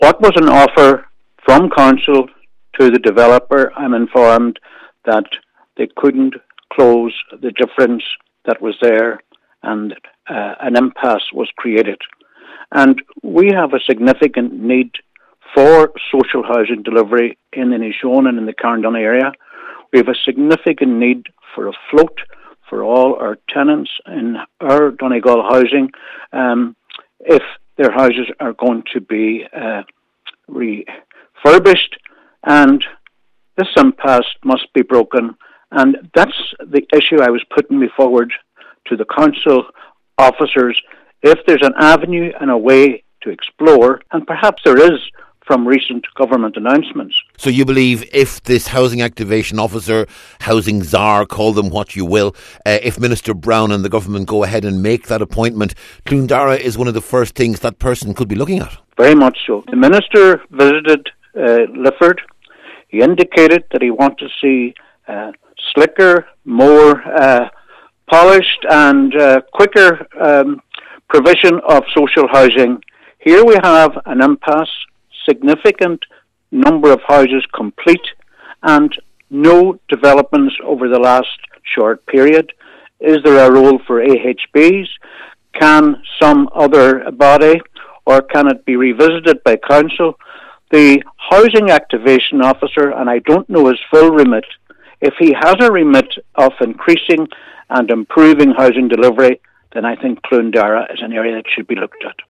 At a recent council meeting to discuss housing, Cllr Albert Doherty said this is an issue which needs to be resolved, and suggested that new Housing Activation Officer should make it one of their first projects……….